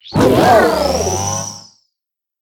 Cri de Miraidon Mode Monture dans Pokémon HOME.
Cri_1008_Monture_HOME.ogg